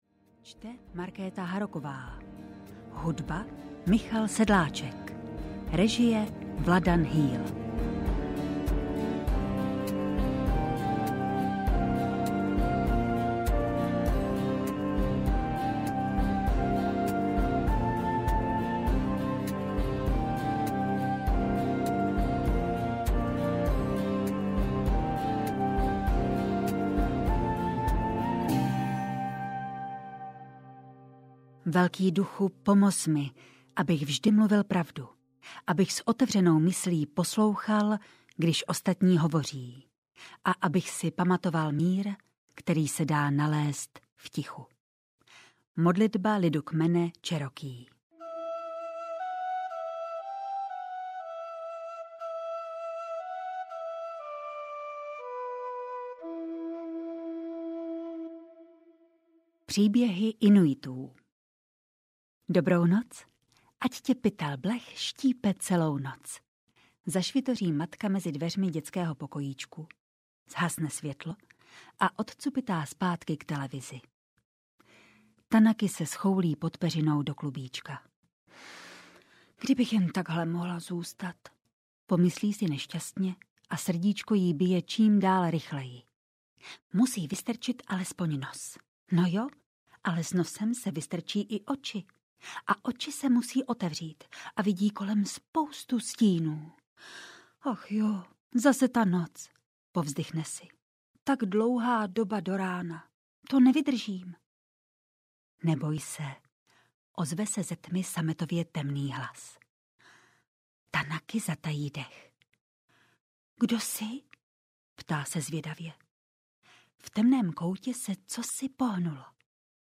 HATI audiokniha
Ukázka z knihy